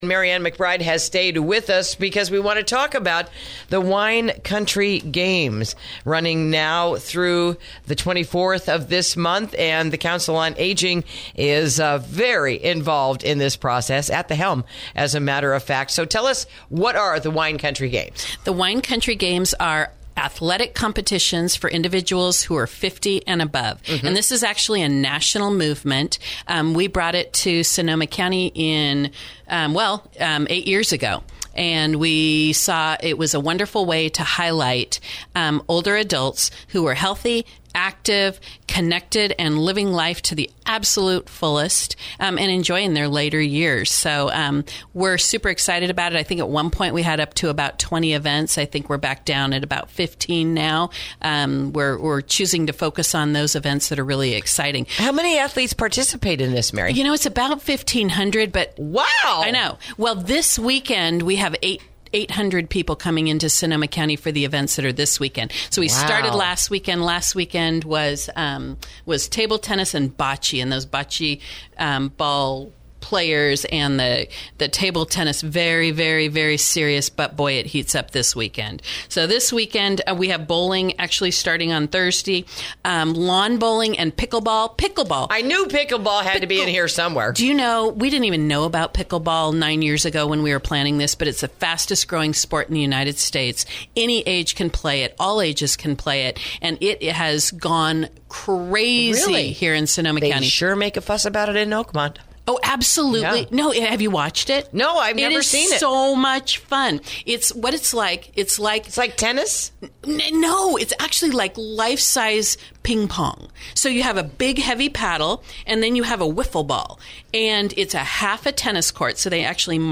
Interview: The Wine Country Games for Those 50+ Are Happening Now and You Can Still Join In